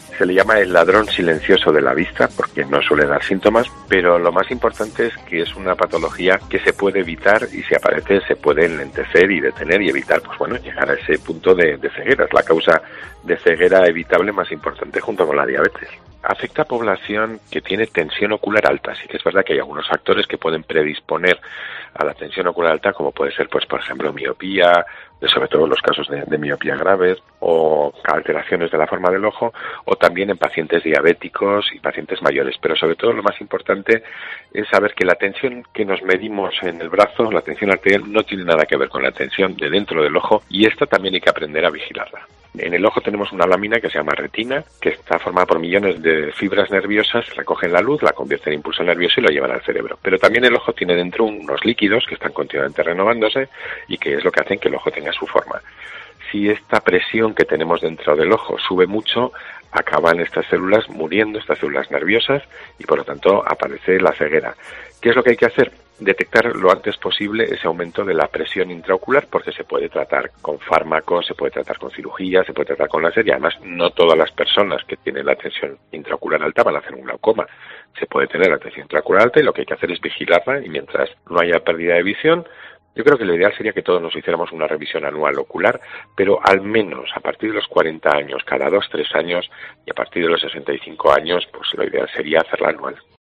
en entrevista a COPE Guadalajara